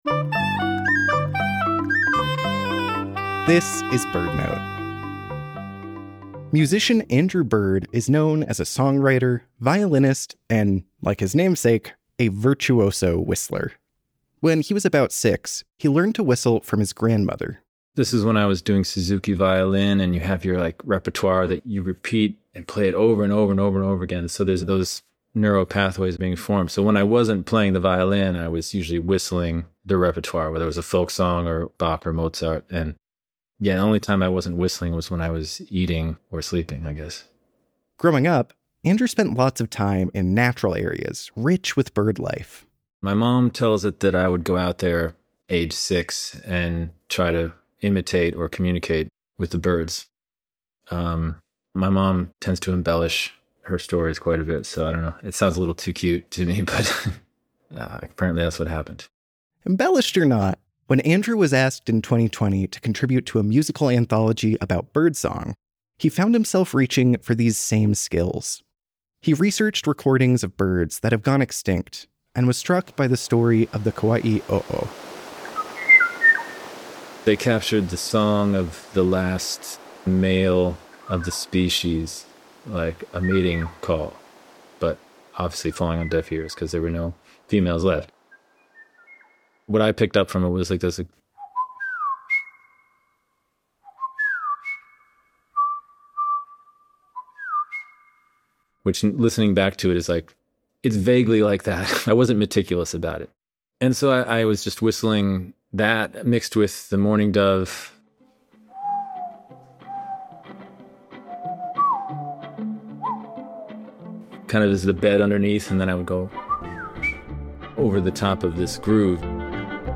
Musician Andrew Bird is known as a songwriter, violinist, and, like his namesake, a virtuoso whistler. He showcases all of these skills in the song Rare Birds, which was created as part of The Birdsong Project in 2020. Whistling melodies inspired by Mourning Doves and the Kaua‘i ‘O‘o alongside his violin, Andrew creates a kind of otherworldly natural soundscape.